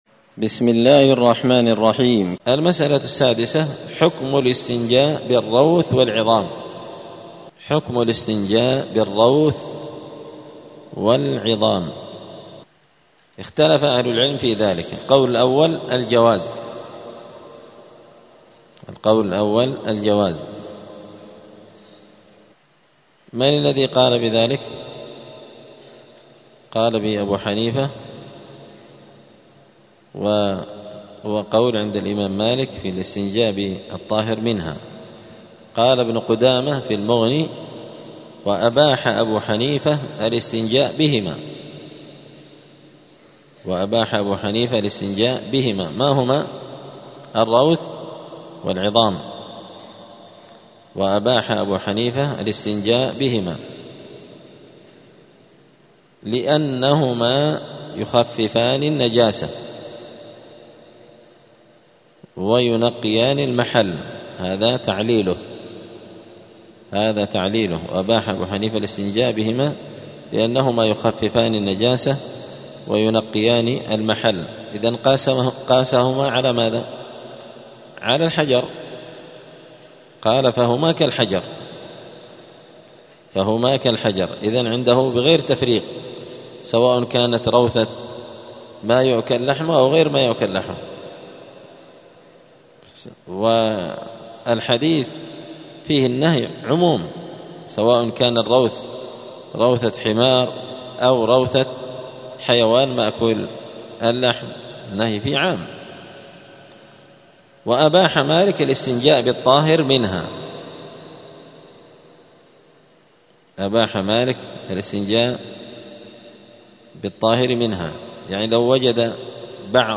دار الحديث السلفية بمسجد الفرقان قشن المهرة اليمن
*الدرس السابع والستون [67] {باب الاستطابة حكم الاستنجاء بالروث والعظم}*